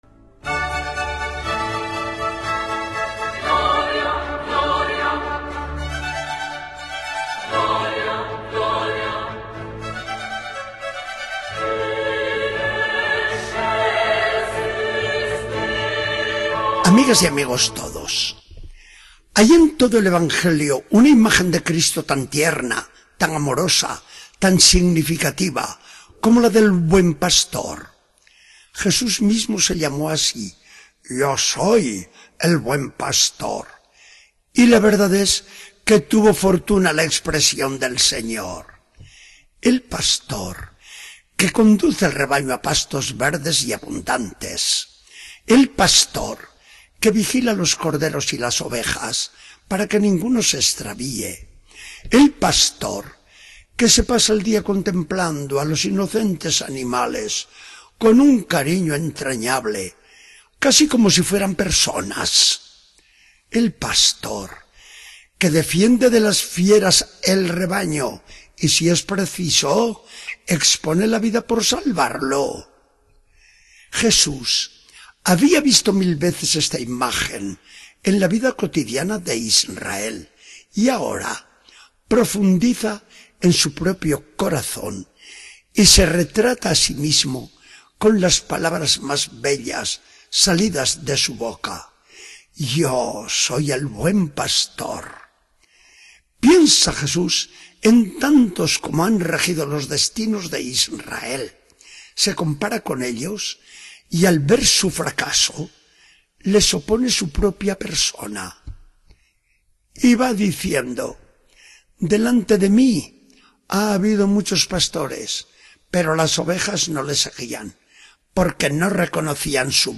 Charla del día 11 de mayo de 2014. Del Evangelio según San Juan 10, 1-10.